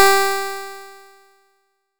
nes_harp_G4.wav